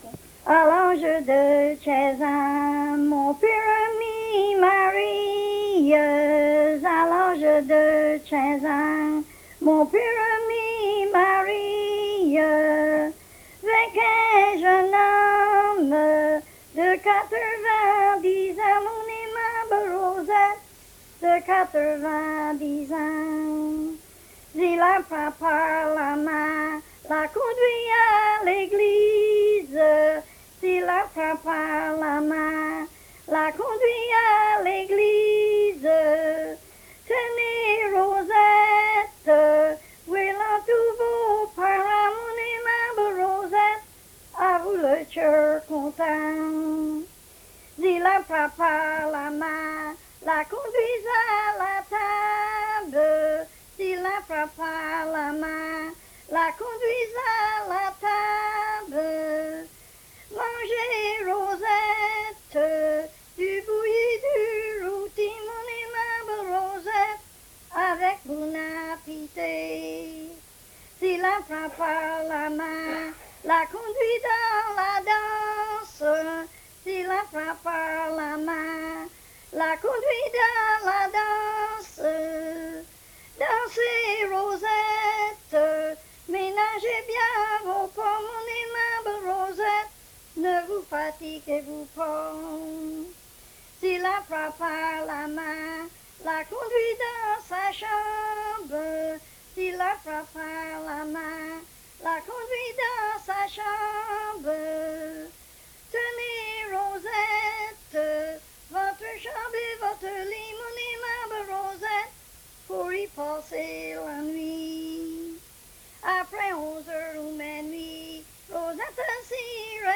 Chanson Item Type Metadata
MUN Folklore and Language Archive